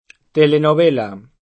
telenovela [port. tëlënuv$l9; sp.
telenoB%la] s. f.; pl. telenovelas [port. tëlënuv$l9š; sp. telenoB%laS] — anche italianizz., quanto alla forma, in telenovela [telenov$la] (meno com. telenovella [telenov$lla]), pl. -le — err. l’uso di telenovelas come singolare